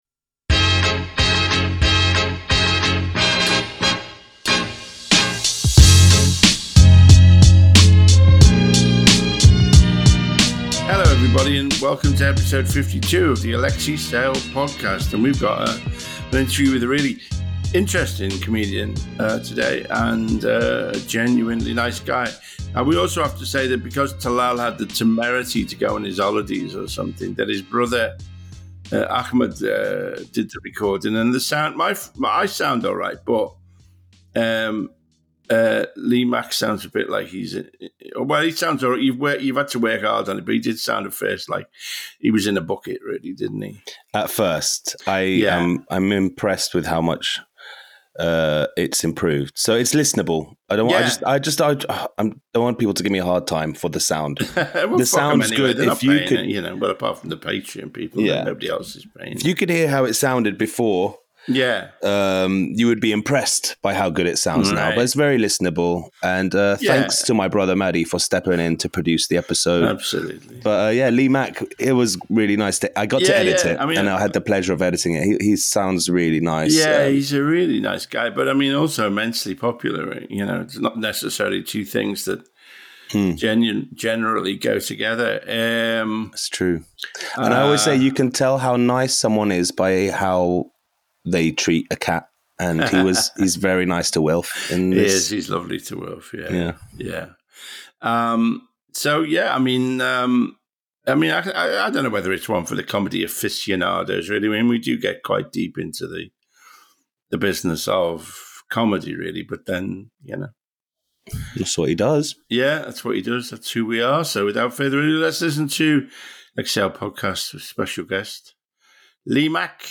Alexei sits down with Lee Mack to explore his early life and career.